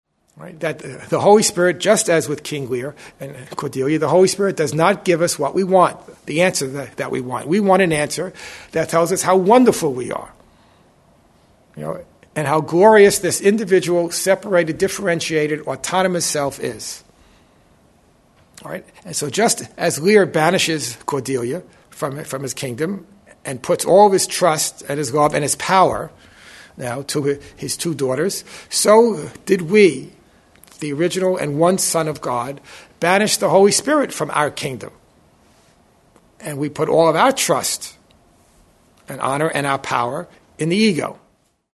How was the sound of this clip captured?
Original Workshop Date: 12/2001